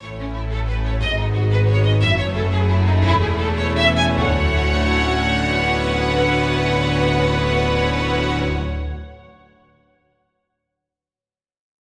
Category 🎵 Music